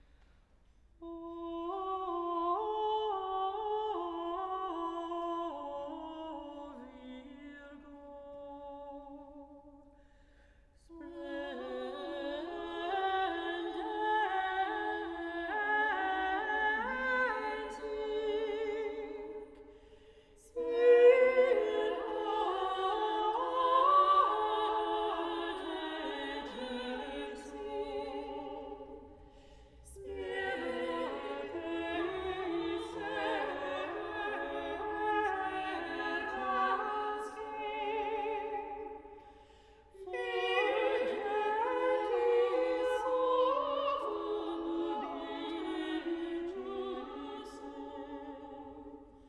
Vox Pix 2011 Wyselaskie Auditorium University of Melbourne